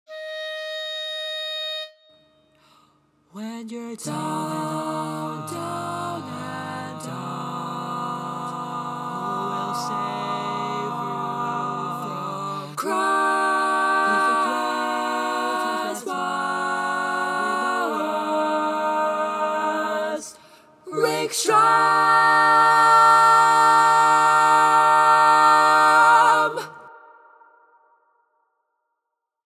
Key written in: E♭ Major
How many parts: 4
Type: Barbershop